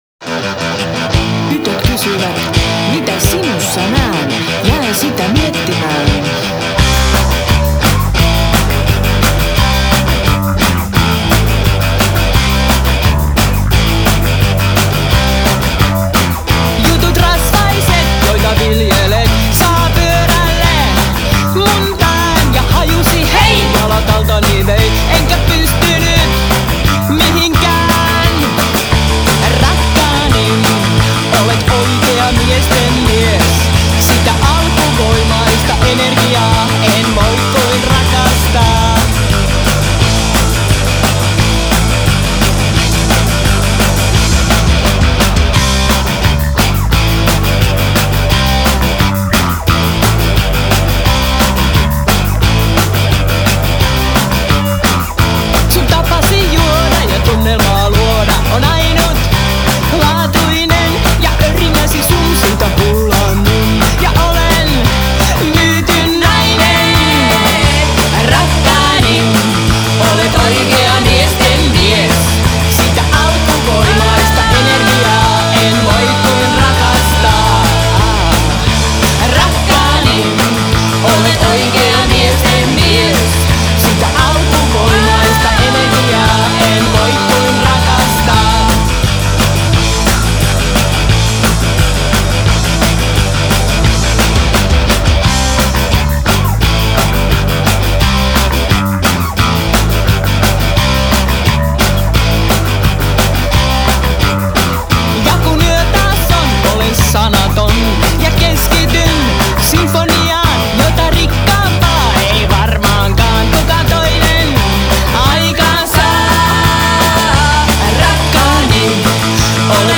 kitara, taustalaulut
basso, taustalaulut
rummut, taustalaulut
treenikämpällä ja Riku-studiossa